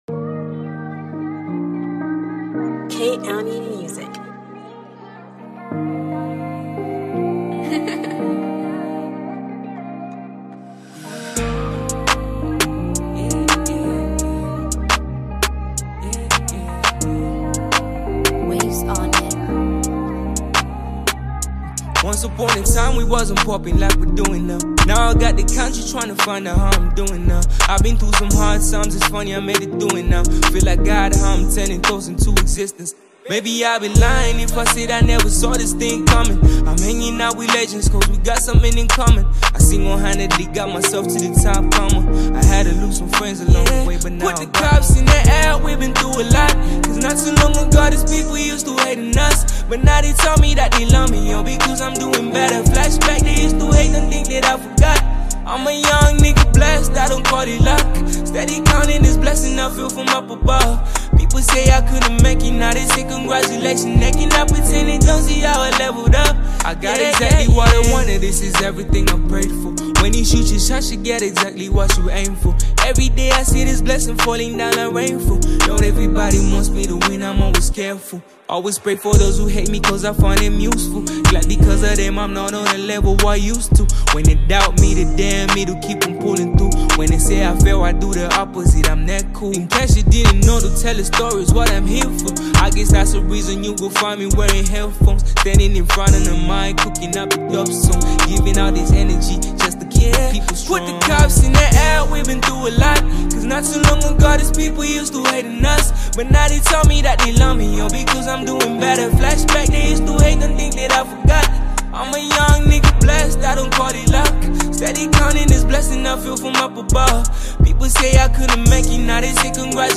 is an uplifting and festive track